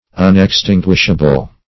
Search Result for " unextinguishable" : The Collaborative International Dictionary of English v.0.48: Unextinguishable \Un`ex*tin"guish*a*ble\, a. Inextinguishable.
unextinguishable.mp3